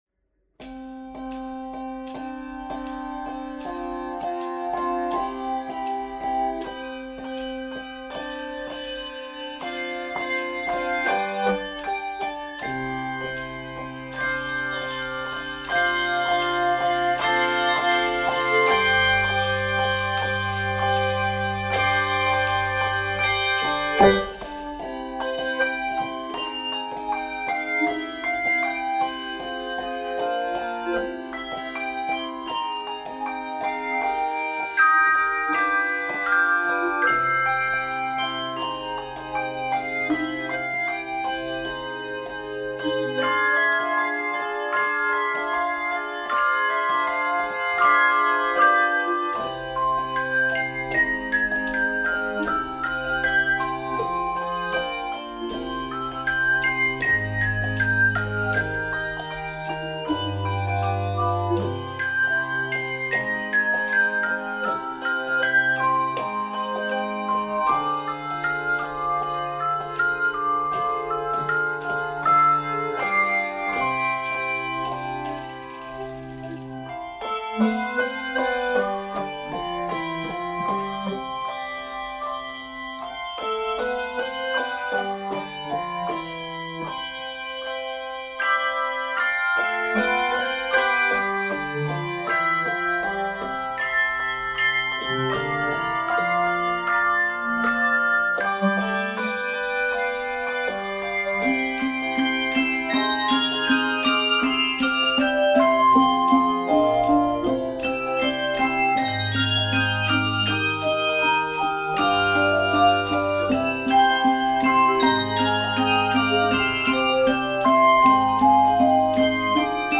Voicing: Handbells 3-7 Octave